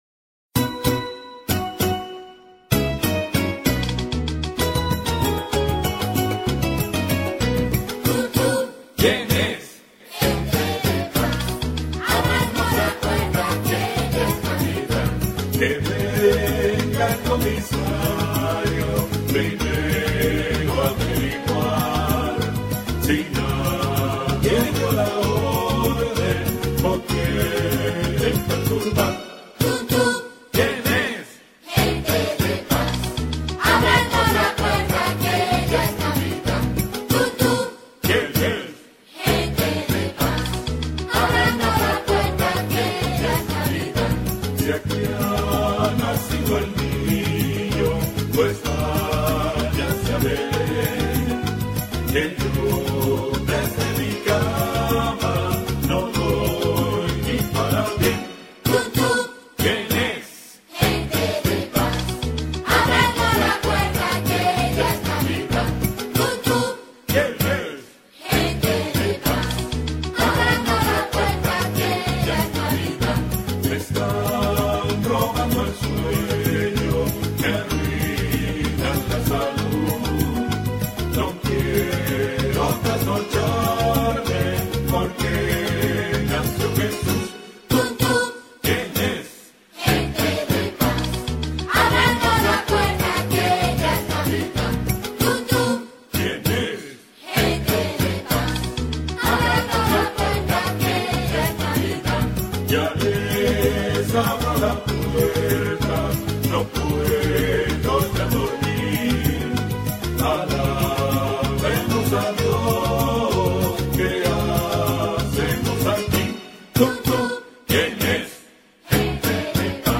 Volver a Podcast Ver todo Tun Tun on 2008-12-25 - Villancicos Descargar Otros archivos en esta entrada Nuestras Creencias Las creencias adventistas tienen el propósito de impregnar toda la vida.